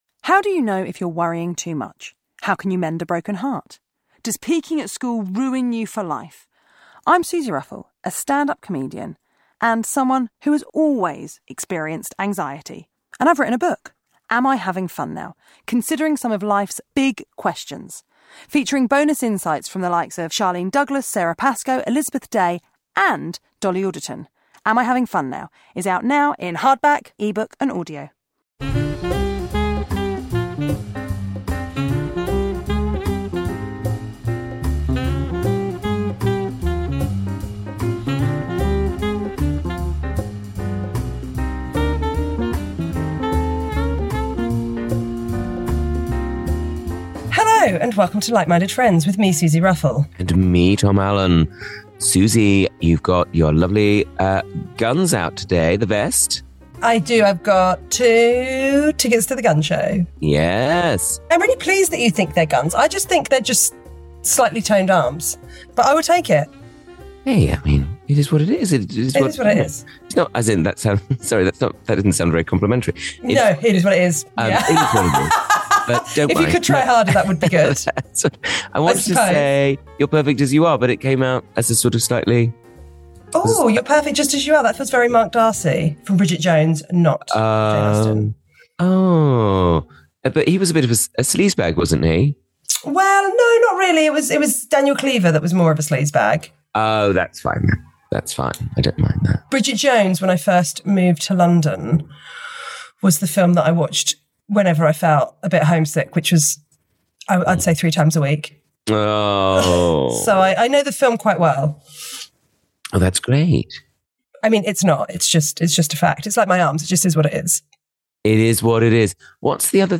Comedians and dearest pals Tom Allen and Suzi Ruffell chat friendship, love, life and culture....sometimes....